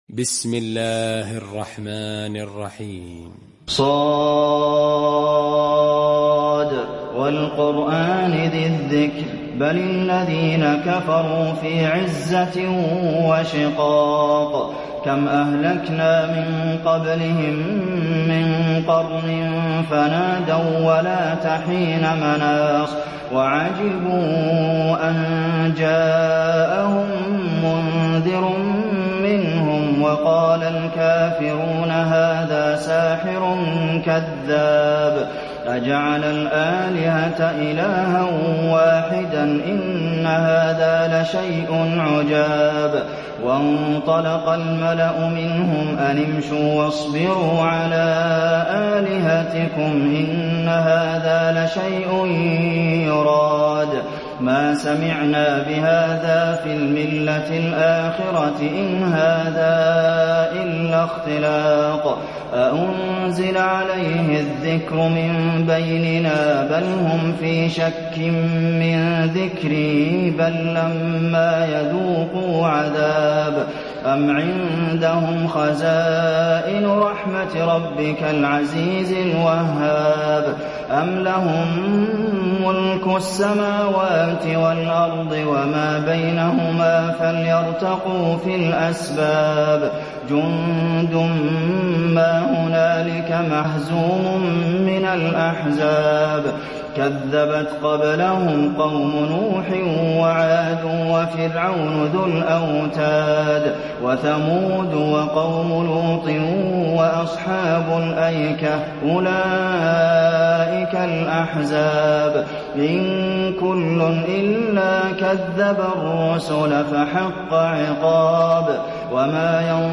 المكان: المسجد النبوي ص